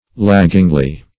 laggingly - definition of laggingly - synonyms, pronunciation, spelling from Free Dictionary Search Result for " laggingly" : The Collaborative International Dictionary of English v.0.48: Laggingly \Lag"ging*ly\, adv. In a lagging manner; loiteringly.